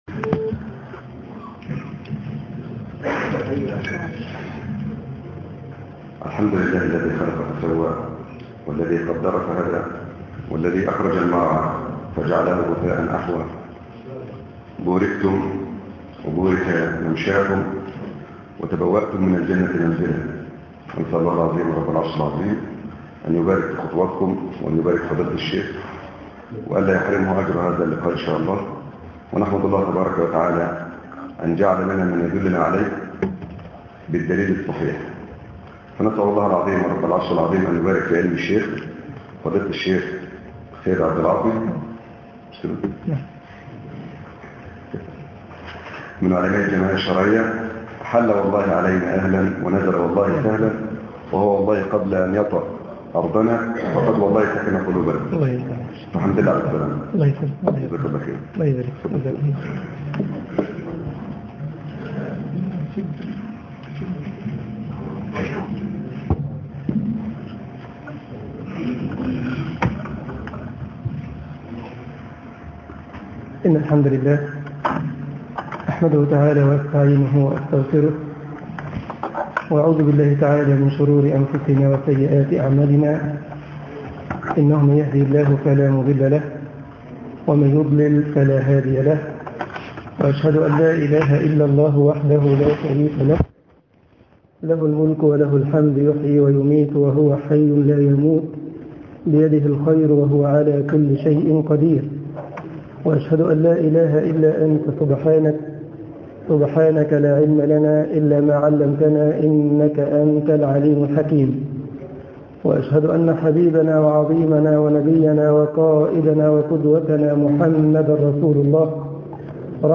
ماذا معك من القران ــ درس طباعة البريد الإلكتروني التفاصيل كتب بواسطة: admin المجموعة: مواضيع مختلفة - رقائق Download درس بطنطا بسيون التفاصيل نشر بتاريخ: الثلاثاء، 13 نيسان/أبريل 2010 23:41 الزيارات: 3178 السابق التالي